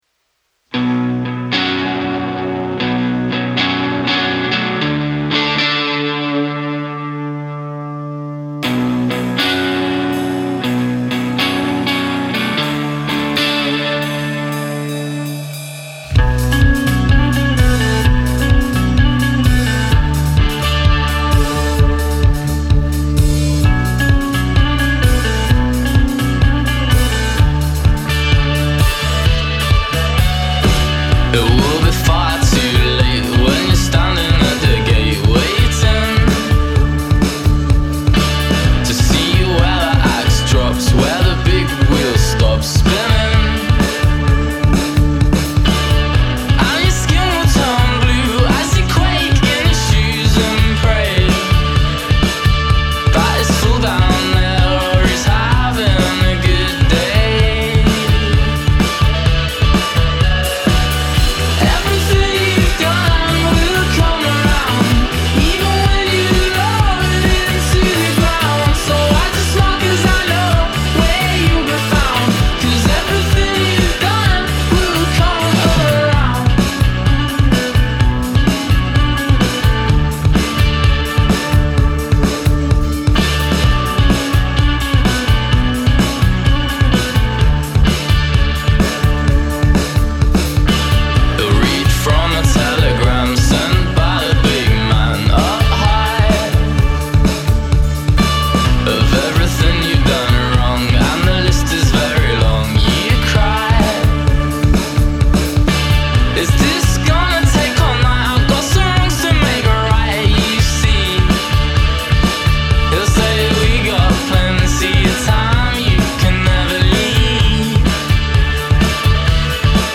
This dancey indie tune